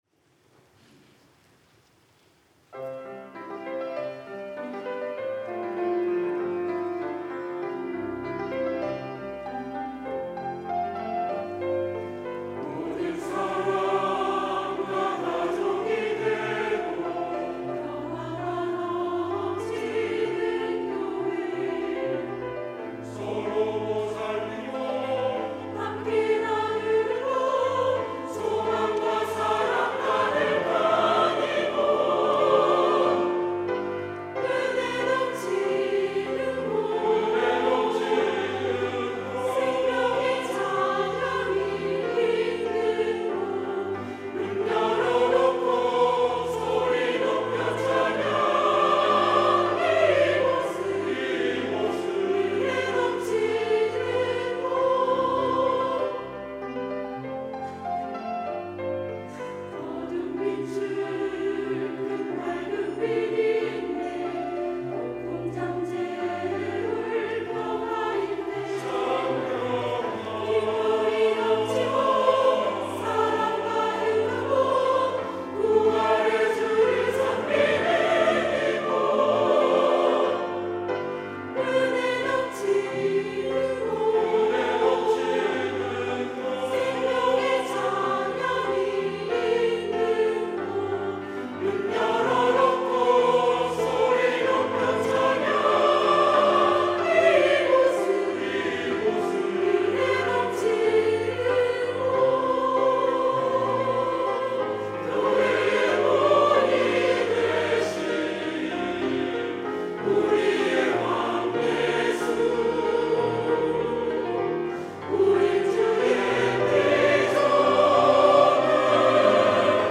시온(주일1부) - 은혜의 성전
찬양대